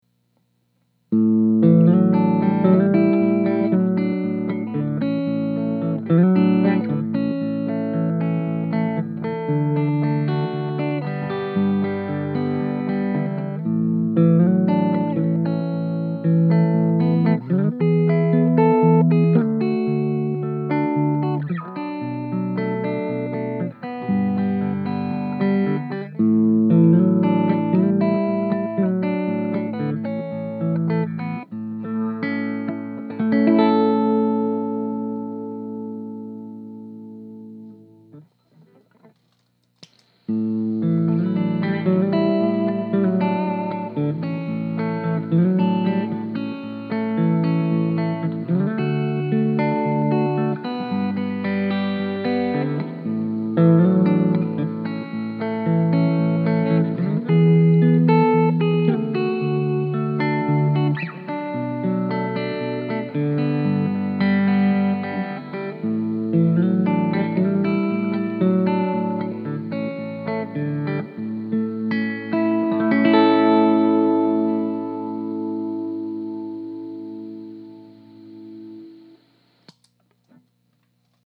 I used the 1-Spot in my clips, and when the Spring Fever was activated, there was a slight, but noticeable hiss.
The clips below were all recorded using my 1958 Fender Champ output to a Jensen Jet Falcon 1 X 12. I start out each clip with a dry signal, then play it again with some “grease.”
Les Paul, Middle Pickup, Fingerstyle. Reverb: 11am, Mix:10 am
sf_clean_fingerstyle_lp.mp3